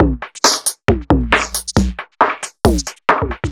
Index of /musicradar/uk-garage-samples/136bpm Lines n Loops/Beats
GA_BeatRingB136-03.wav